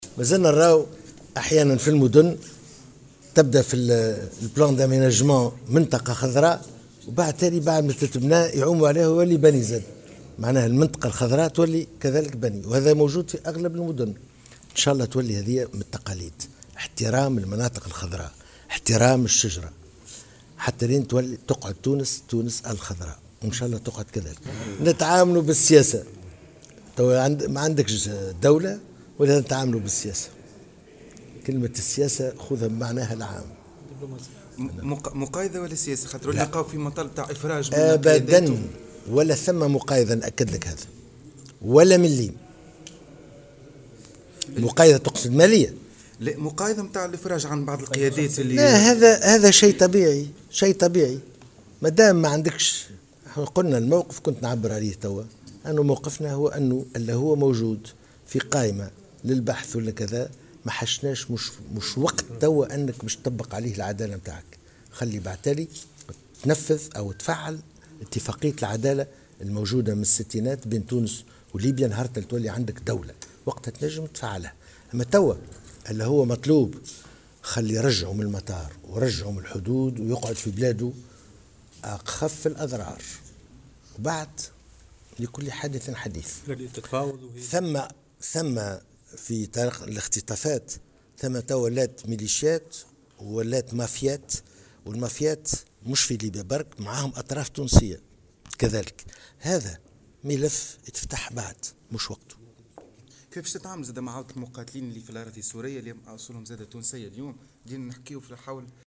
قال وزير الخارجية، الطيب البكوش، في تصريح لمراسل الجوهرة أف أم، لدى إشرافه صباح اليوم الأحد على الاحتفال بعيد الشجرة في القيروان، إن تونس تتعامل بمبدأ "أخف الأضرار" في قضايا رعاياها المختطفين في ليبيا.